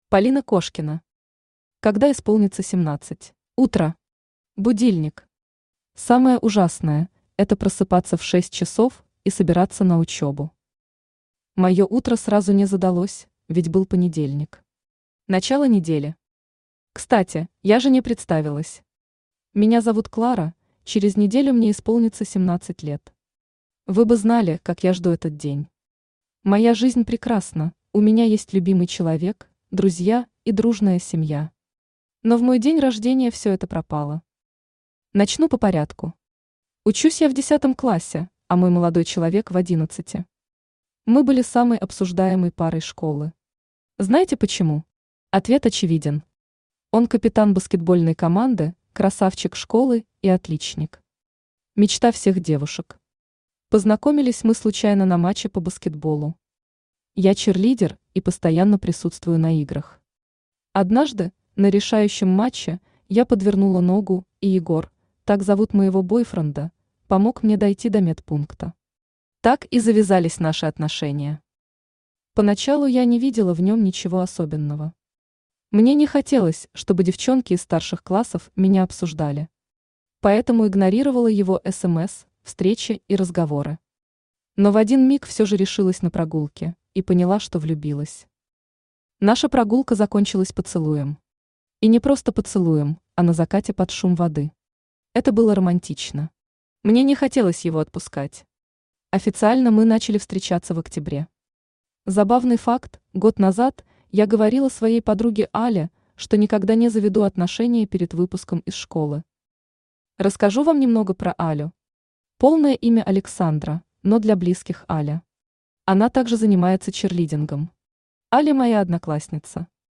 Аудиокнига Когда исполнится 17 | Библиотека аудиокниг
Aудиокнига Когда исполнится 17 Автор Полина Кошкина Читает аудиокнигу Авточтец ЛитРес.